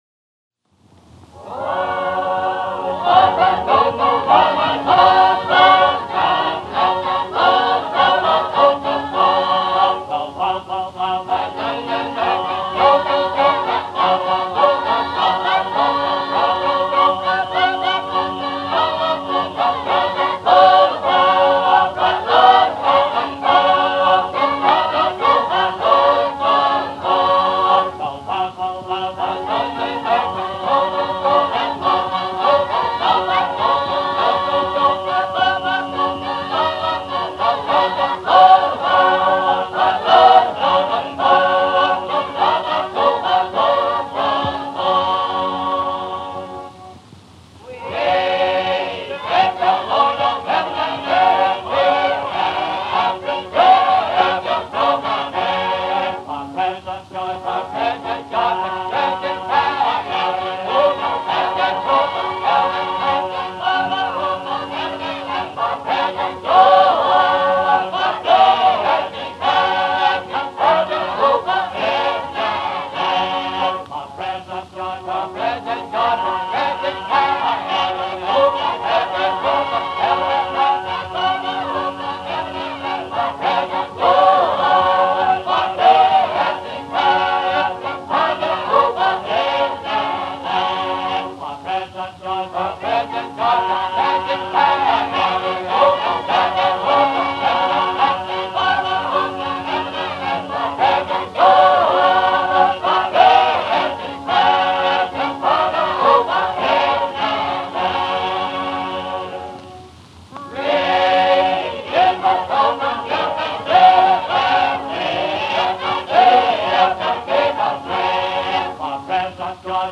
Sacred Harp Singing